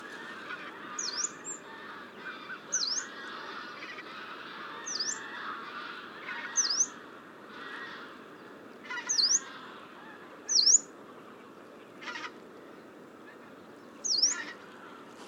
bird call I ‘ve recognised after I had opened the car door were calls of a
Here is a short recording of one of the two birds I heard immediately:
PFR09538, 2-00, 130928, Yellow-browed warblers Phylloscopus inornatus, call,
Flachwasserzone Mannhausen, Telinga parabolic reflector